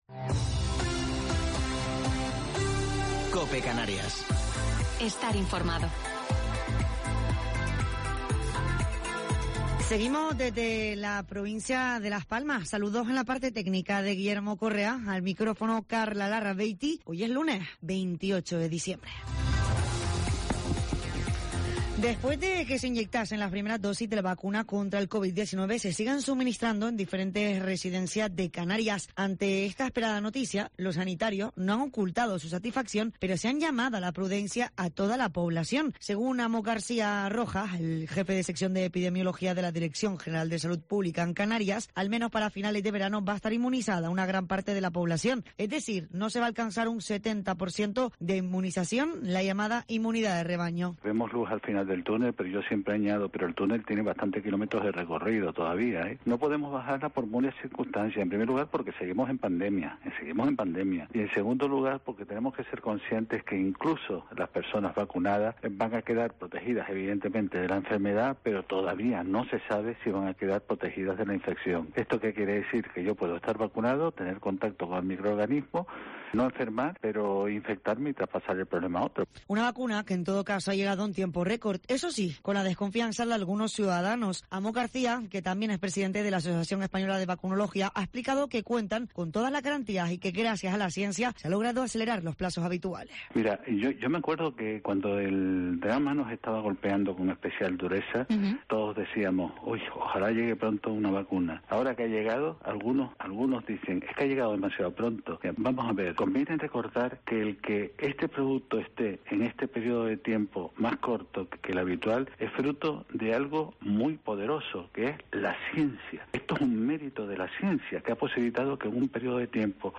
Informativo local 28 de Diciembre del 2020